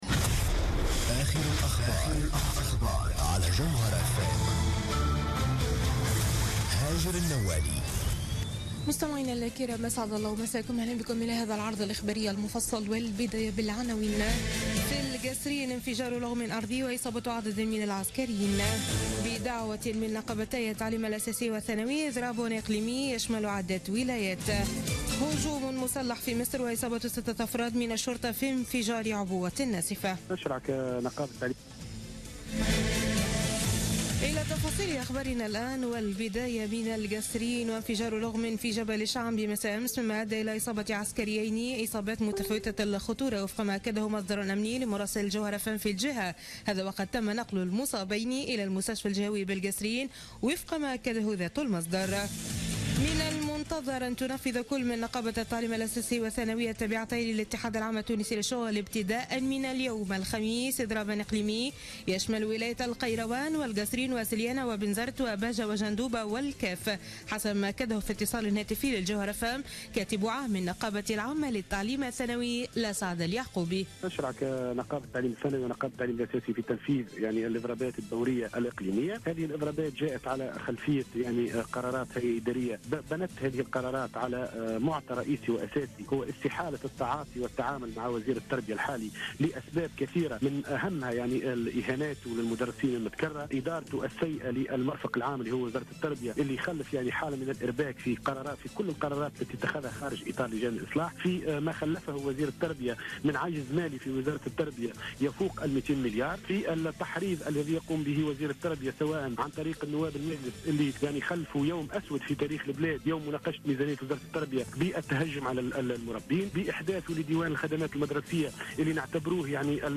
نشرة أخبار منتصف الليل ليوم الخميس 15 ديسمبر 2016